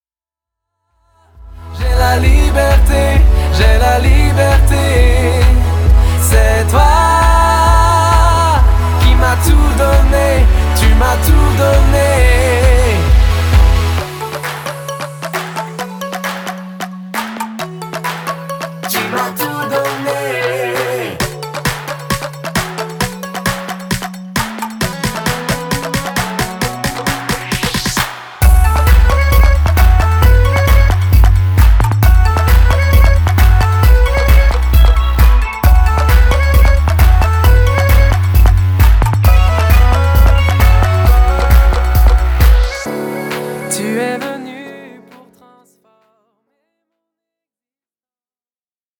chants de louange aux accents électro